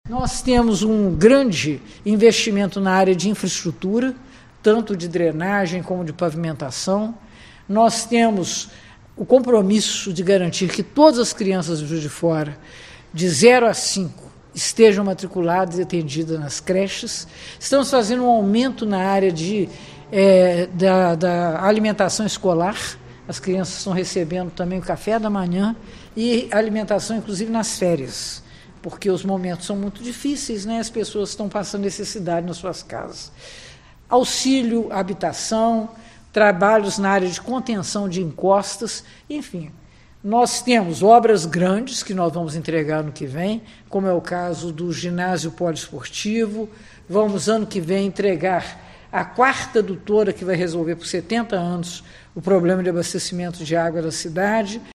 A prefeita de Juiz de Fora, Margarida Salomão (PT) concedeu entrevista à imprensa para falar sobre o orçamento do executivo municipal e dar um panorama da gestão.
02-Entrevista-Margarida-2.mp3